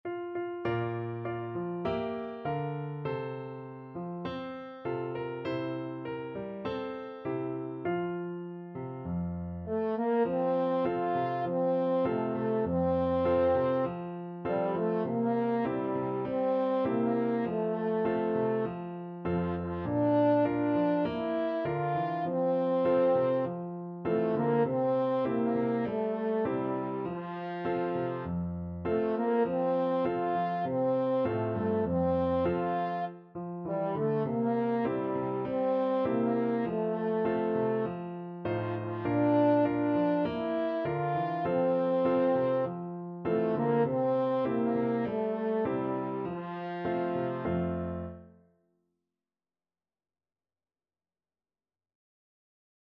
French Horn
Moderato
4/4 (View more 4/4 Music)
F major (Sounding Pitch) C major (French Horn in F) (View more F major Music for French Horn )
Traditional (View more Traditional French Horn Music)